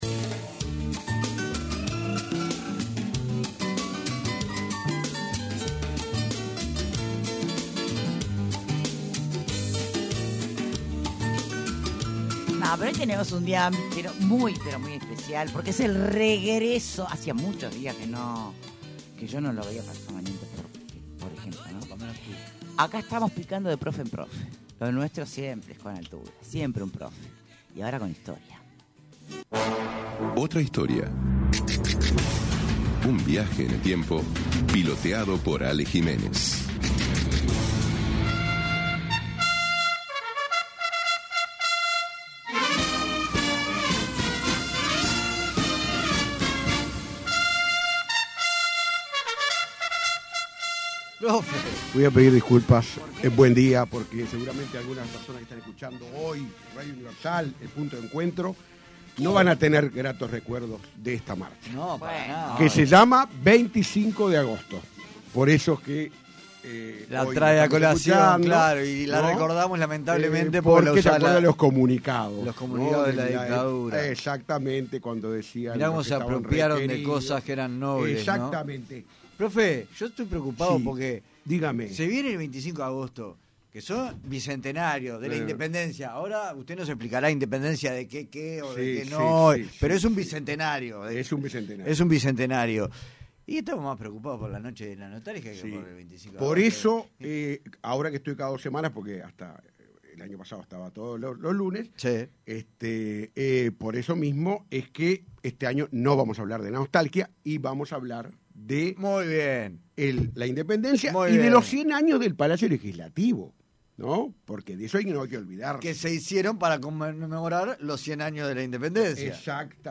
Entrevista completa En el bicentenario de la Declaratoria de la Independencia